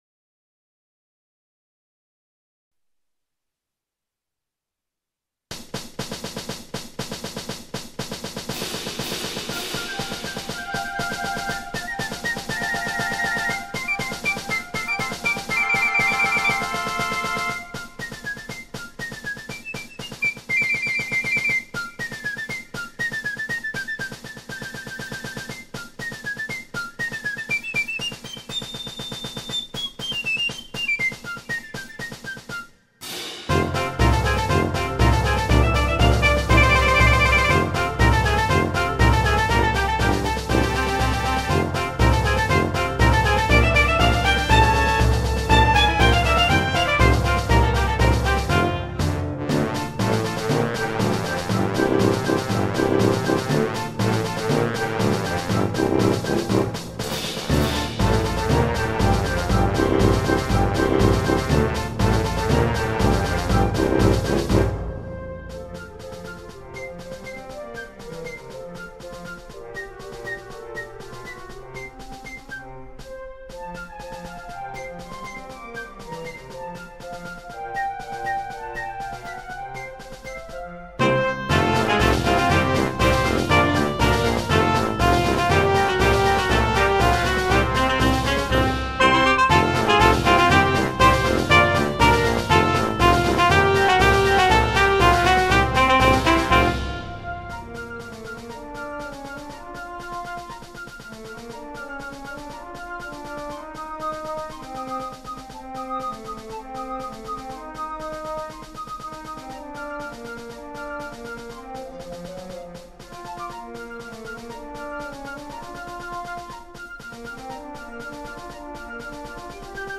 I compose this for the little marching band or boys-scout band ,so the music isn't as strong as the real march orchestra, but still have it's spirit and lifely